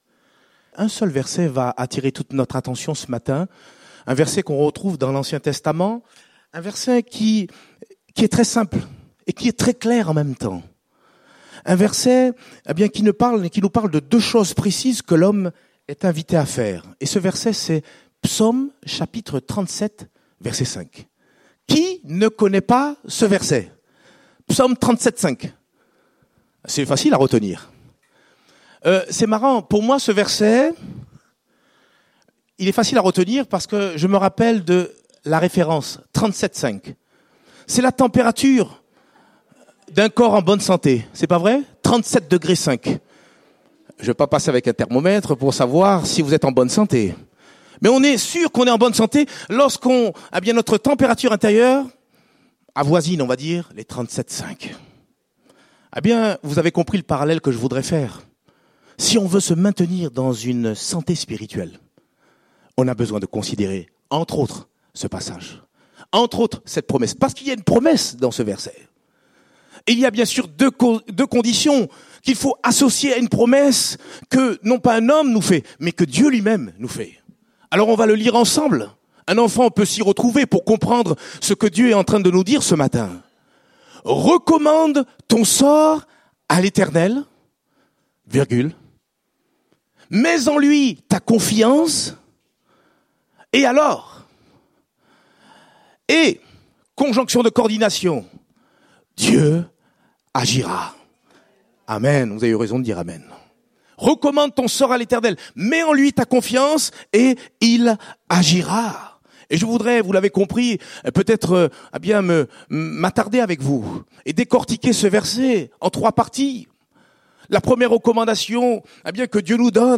Date : 14 octobre 2018 (Culte Dominical)